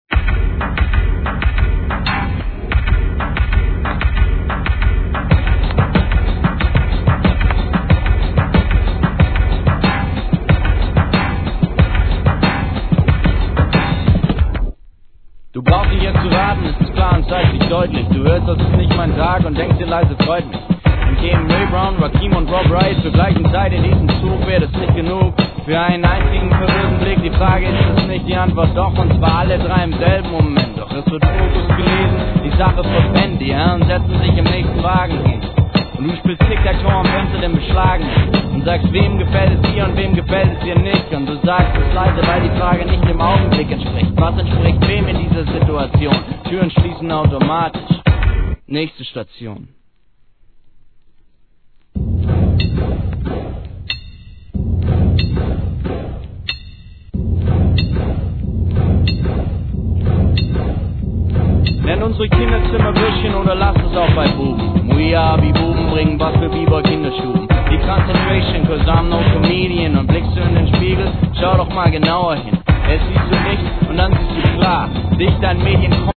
HIP HOP/R&B
独語での流れるようなRAP、独特なプロダクションも必聴!